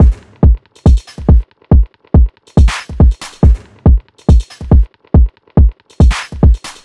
Category 🎵 Music
beat beats drumkit fast Gabber hardcore House Jungle sound effect free sound royalty free Music